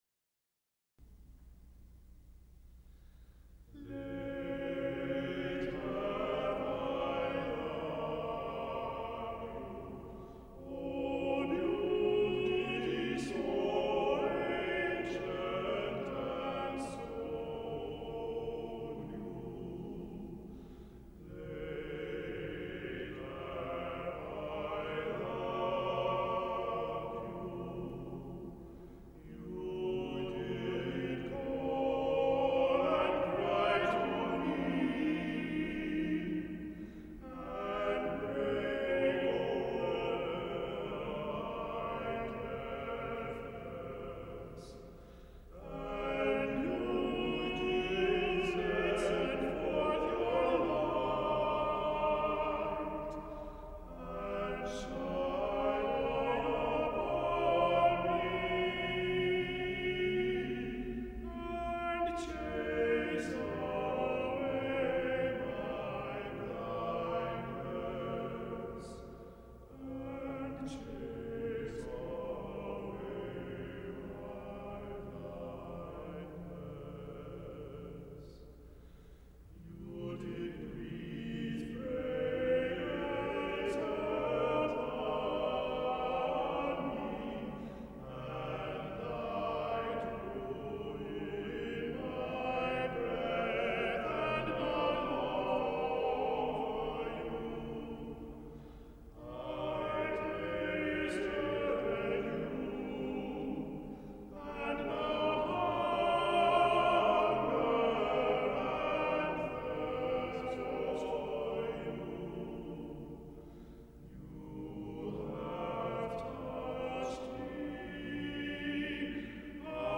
Late Have I Loved You Read through - 8 voices